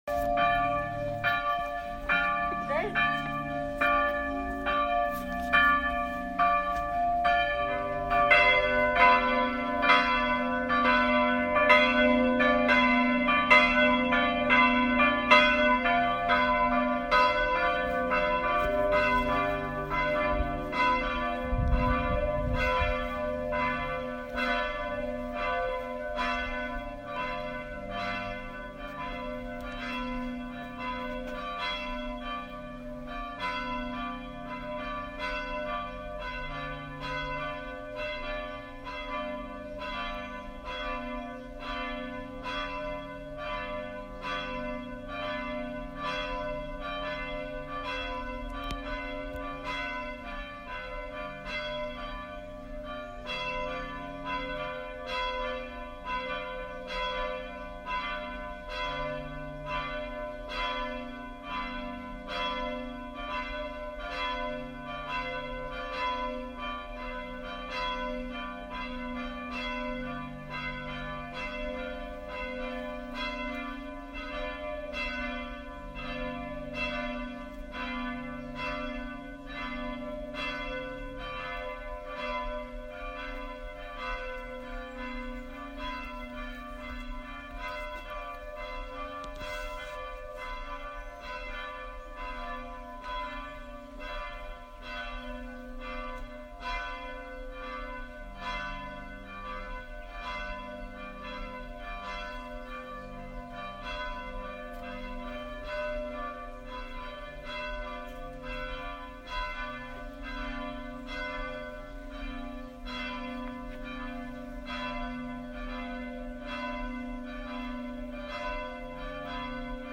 Öffnungszeiten: Donnerstags in der Zeit von 13.00 bis 15.00 Uhr MP3 | 3 MB Geläut Königsberg GC
Gelaeut_Koenigsberg.mp3